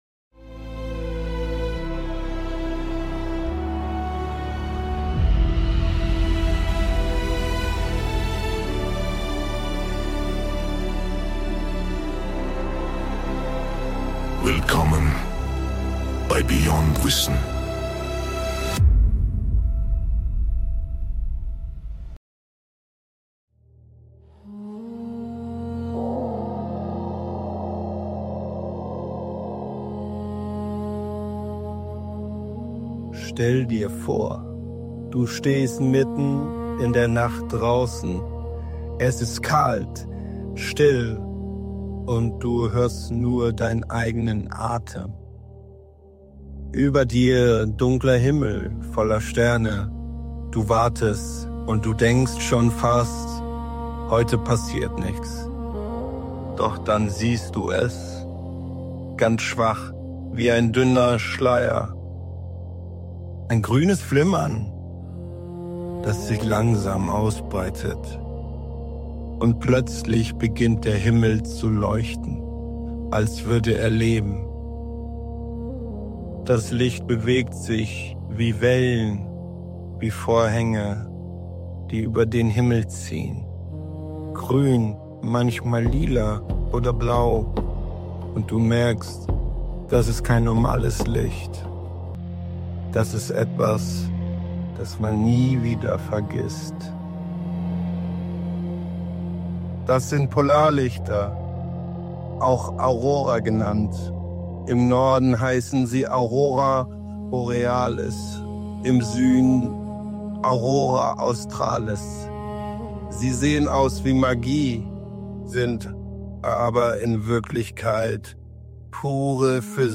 Du erfährst, wie Sonnenenergie, Magnetfelder und unsere Atmosphäre zusammenwirken, warum der Himmel grün, rot oder violett leuchtet und weshalb diese Lichter Menschen seit Jahrhunderten faszinieren. Eine ruhige, epische Audio-Doku zwischen Wissen, Staunen und kosmischer Schönheit – perfekt zum Zuhören!
Musik by Suno.